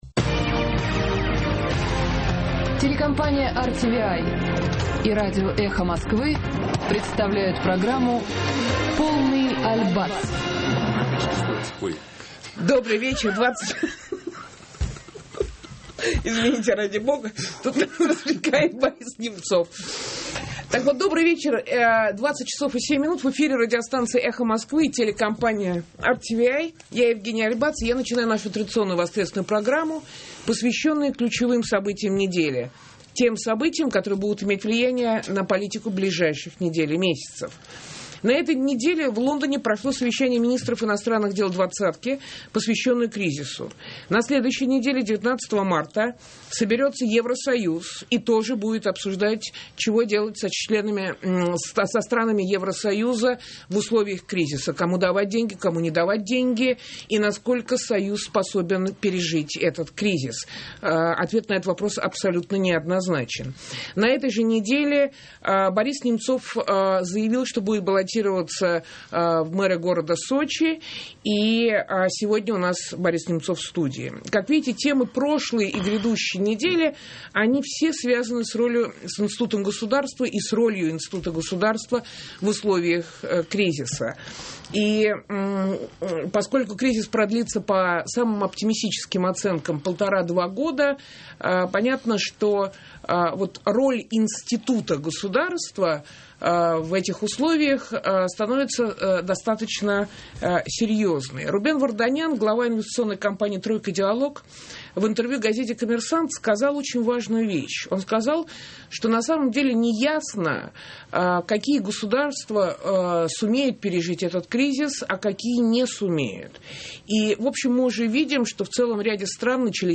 2009-03-15 Скачать СМЕХ В СТУДИИ Е.АЛЬБАЦ